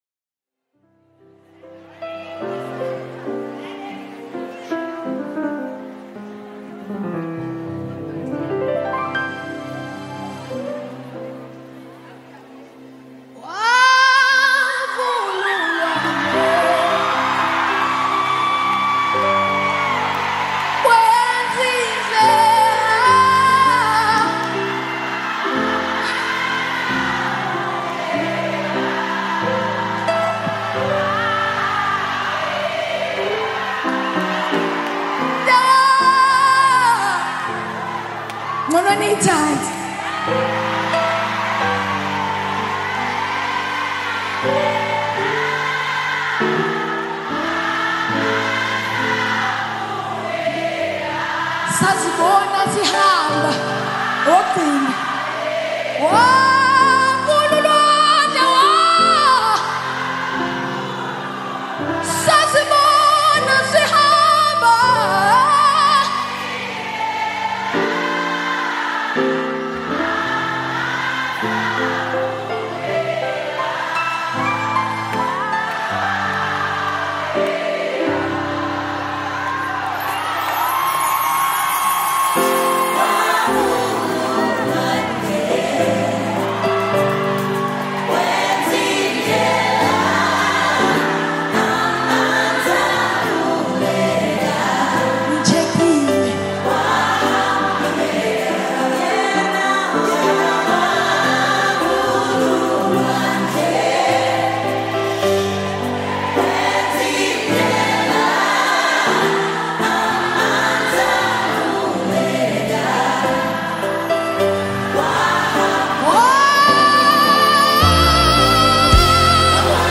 South African Gospel singer